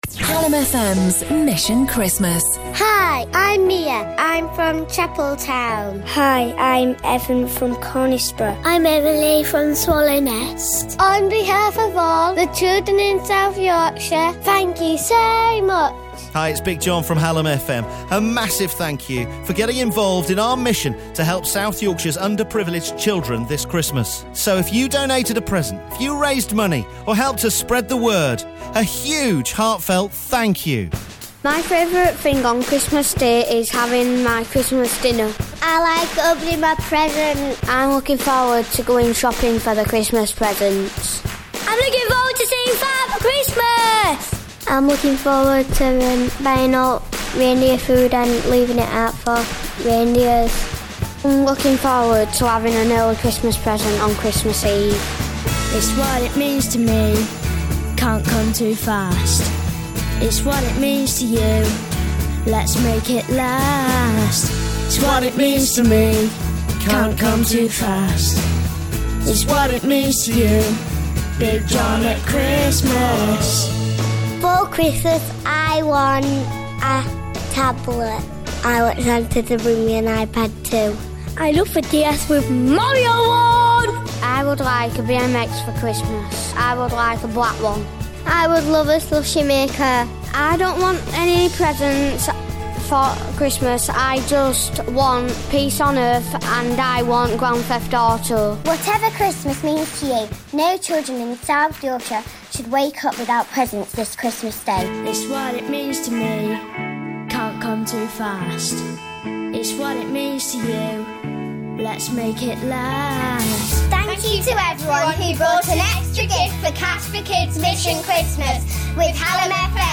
an inspirational song
Children from Sheffield, Barnsley, Rotherham and Doncaster singing about why Mission Christmas was so important!